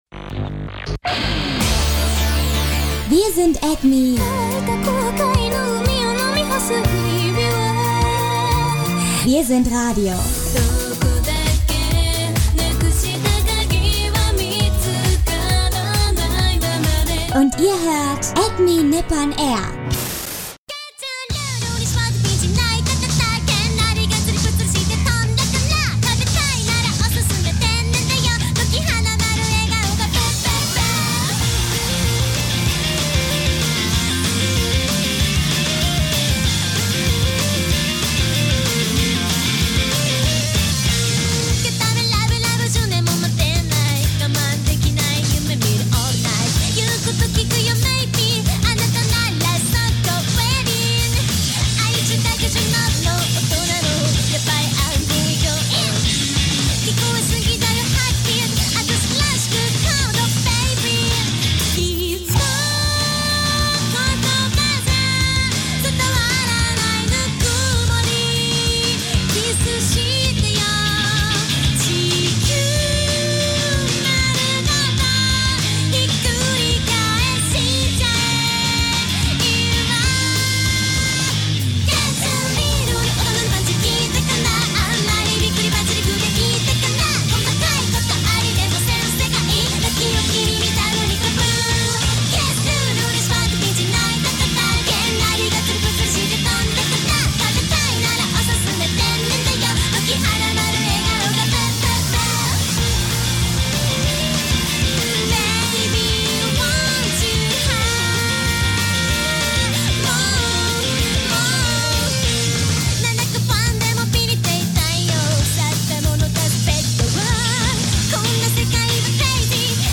Neben den vielen Interviews gibt es natürlich auch wieder Musik und Nachrichten aus Japan und aus der Animeszene.